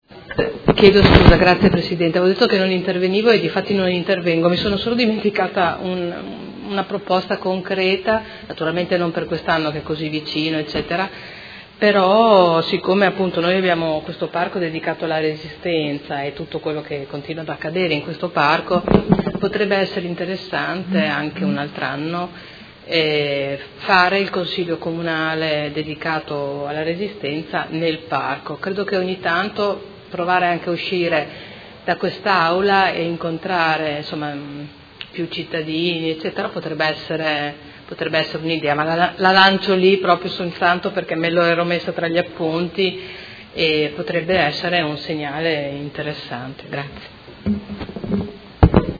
Seduta del 26/03/2018 Conclusioni. Ordine del Giorno presentato dai Consiglieri Liotti, Bortolamasi, Baracchi, Pacchioni, Arletti, Venturelli, Di Padova e De Lillo (PD) avente per oggetto: Ferma condanna degli atti vandalici al Parco della Resistenza e ripristiniamo il Parco insieme